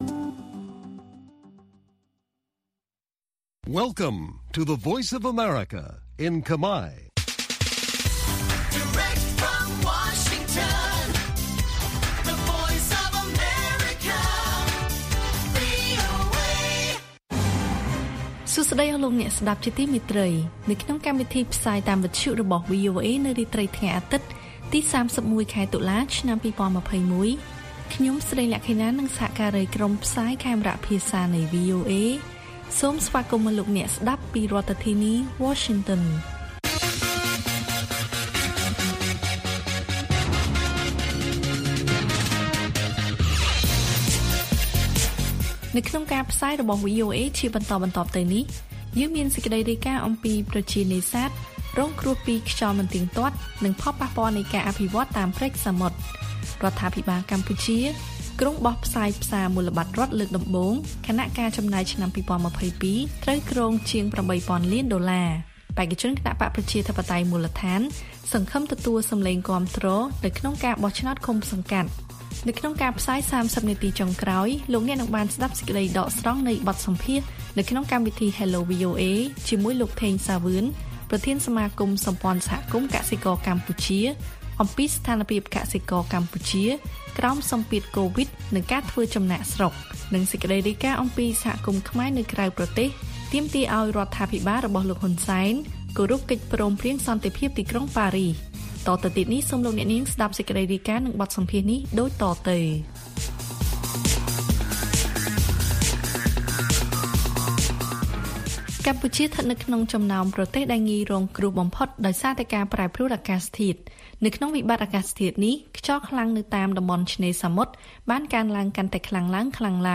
ព័ត៌មានពេលរាត្រី៖ ៣១ តុលា ២០២១
បទសម្ភាសន៍ដកស្រង់ពីកម្មវិធី Hello VOA៖ អ្នកជំនាញថា កសិករកម្ពុជារងបន្ទុកធ្ងន់ធ្ងរពីជំងឺរាតត្បាតកូវីដ១៩។